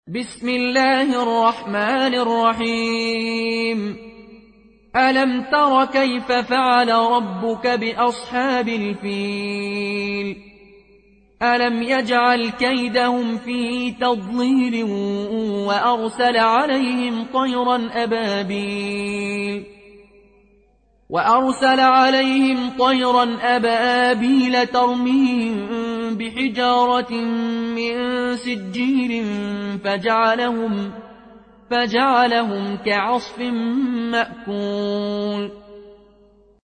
فالون از نافع